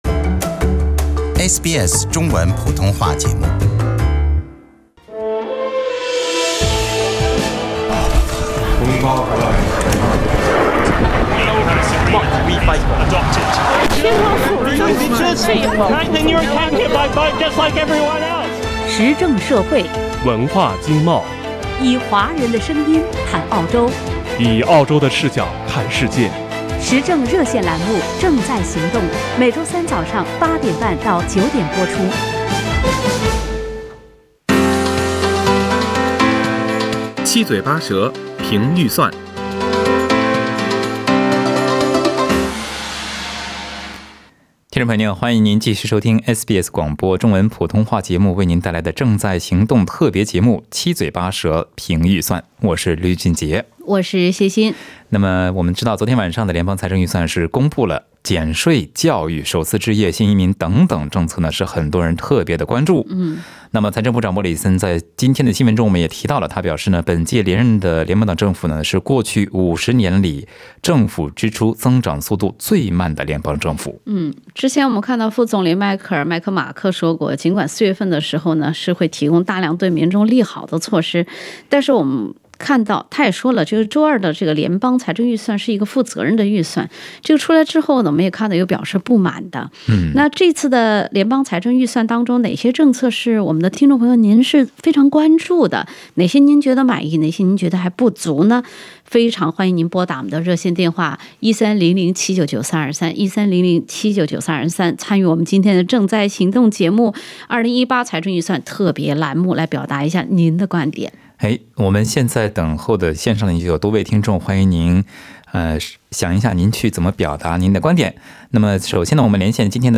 以下是参与本期《正在行动》节目的嘉宾和听众的部分观点：